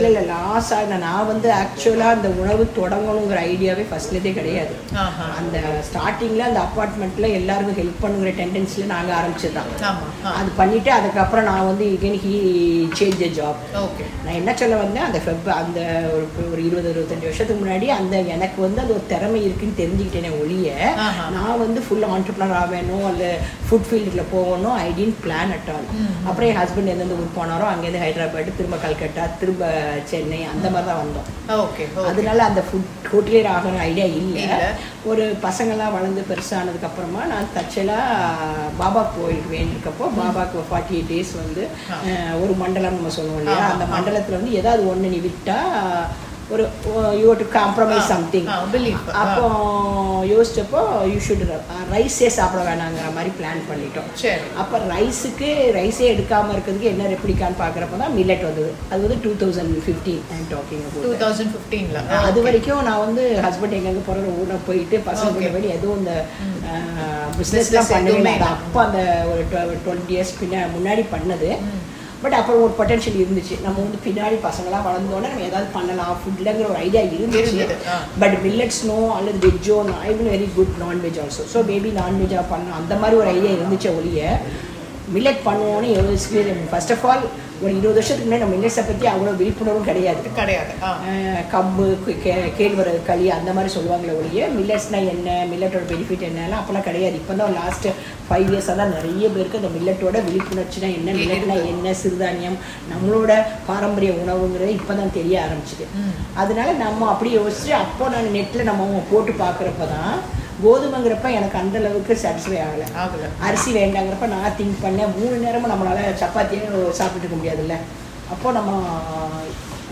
நேர்காணல்கள்